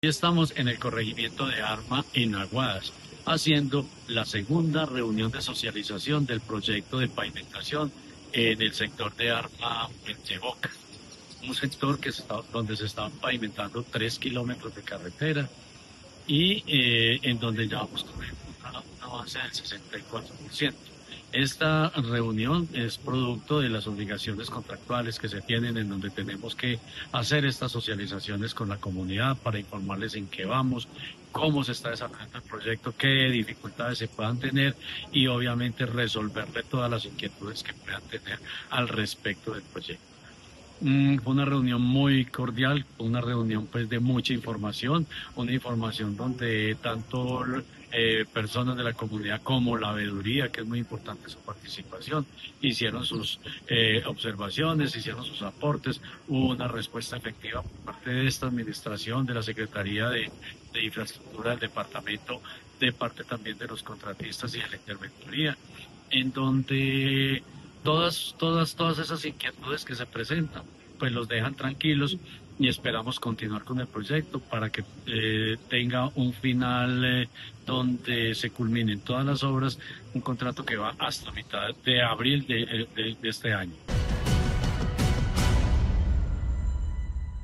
Jorge Ricardo Gutiérrez, secretario de Infraestructura de Caldas